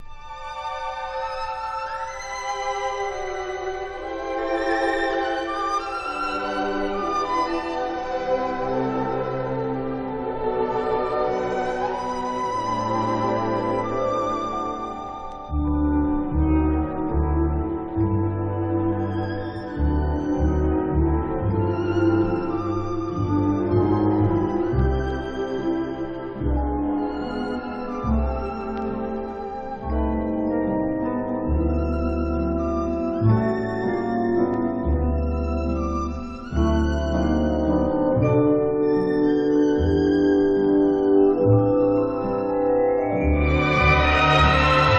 Jazz, Pop, Easy Listening　UK　12inchレコード　33rpm　Stereo